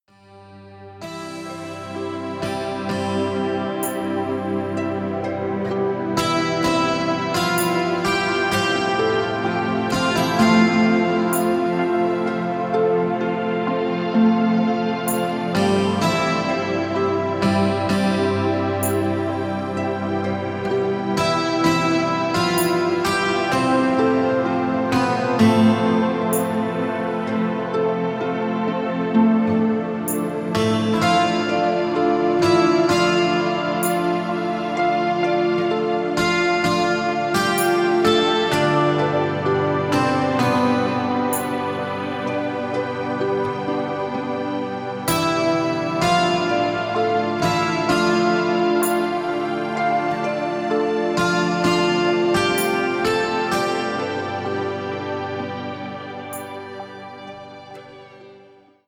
Gefühlvoll arrangierte Instrumentalmusik zum Planschen …